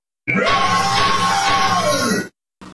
esqueleto escudo Meme Sound Effect
Category: Meme Soundboard